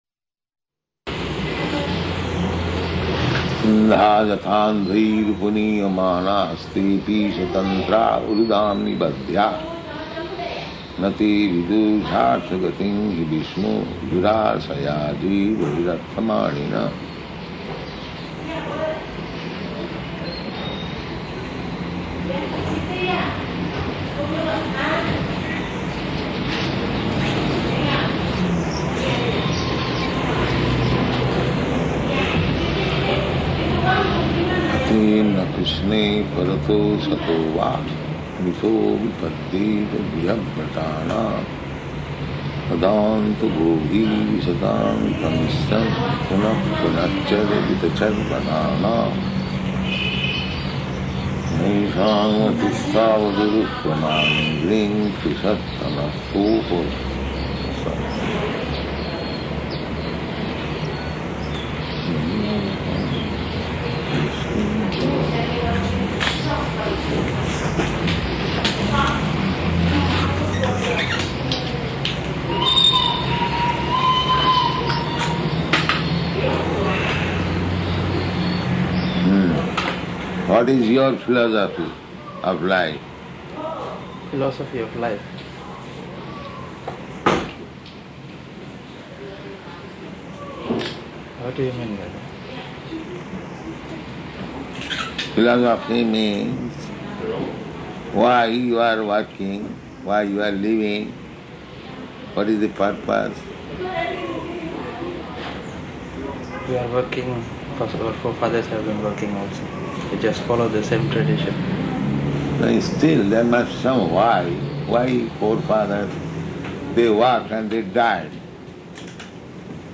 Room Conversation, Śrīmad-Bhāgavatam 7.5.31–33
Type: Conversation
Location: Jakarta